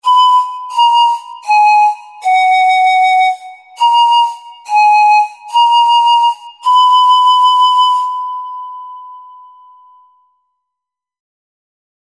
Poniżej zamieszczono przykładowe dźwięki otrzymane przy pomocy modelu fizycznego.
bez artykulacji